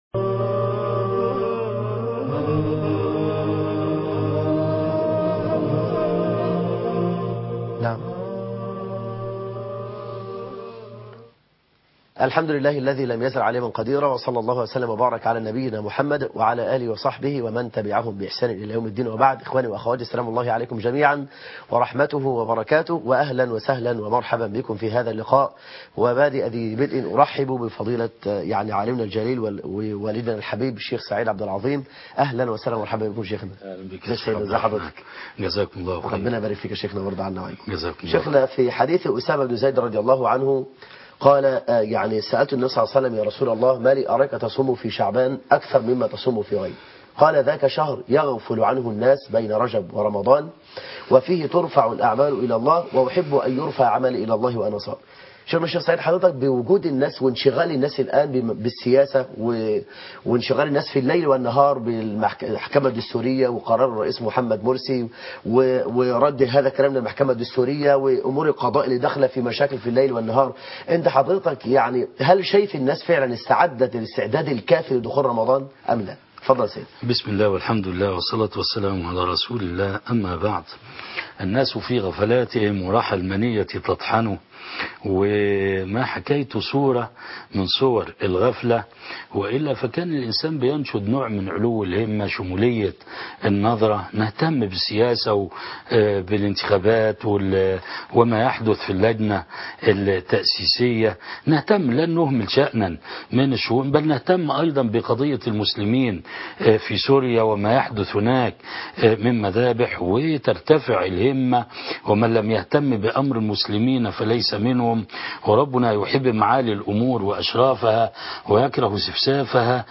لقاء خاص